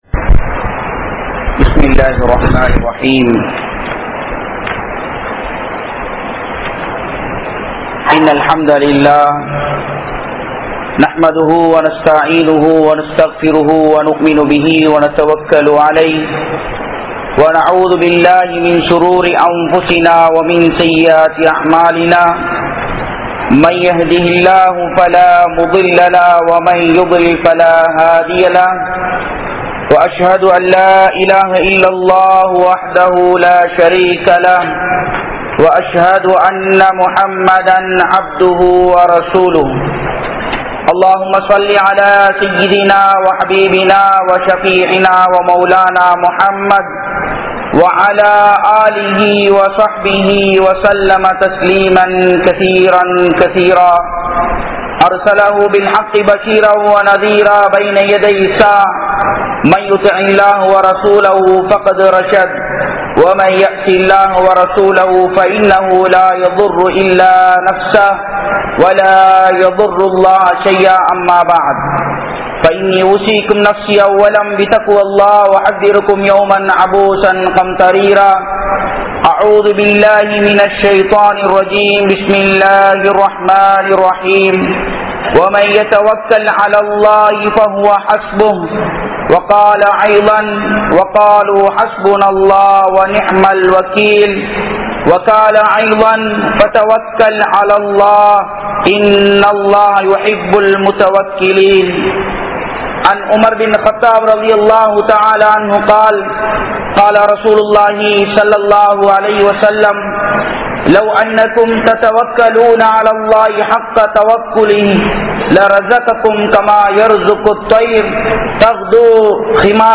Muslimkalaahiya Naam Yaarai Nampa Veandum? (முஸ்லிம்களாகிய நாம் யாரை நம்ப வேண்டும்?) | Audio Bayans | All Ceylon Muslim Youth Community | Addalaichenai
Jamiul Furqan Jumua Masjith